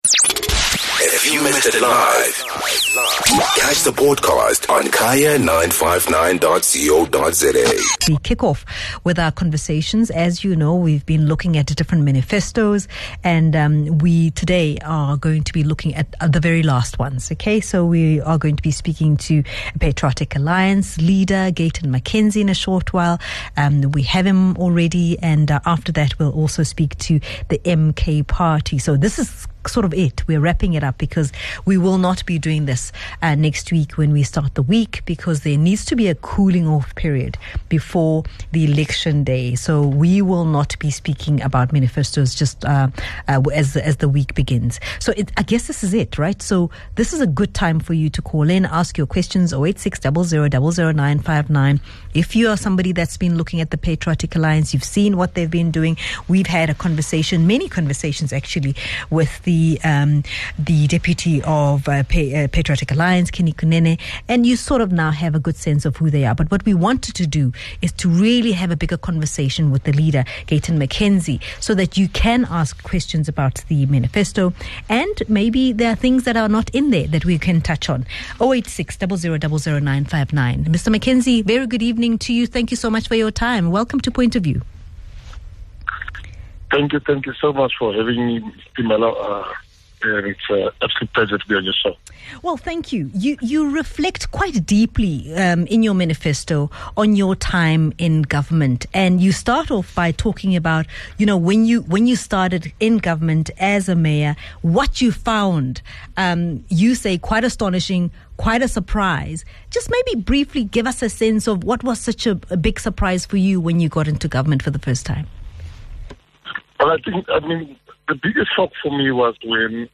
Guest: Gayton McKenzie, Patriotic Alliance Leader